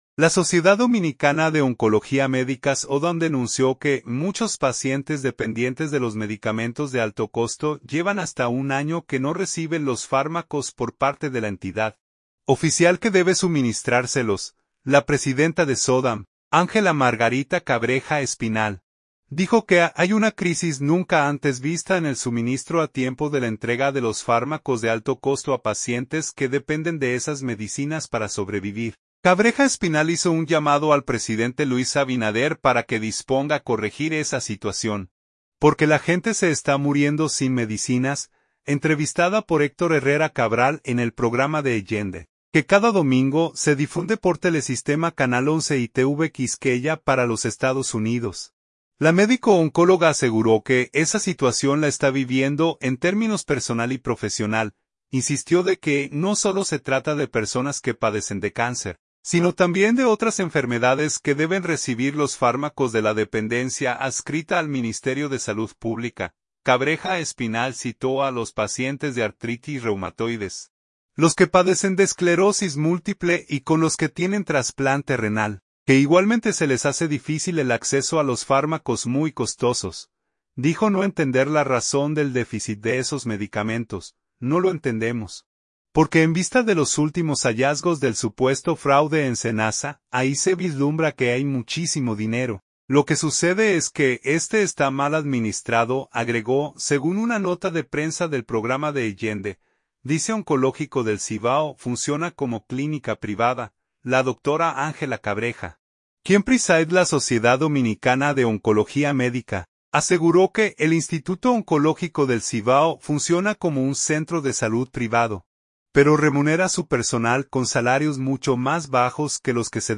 Entrevistada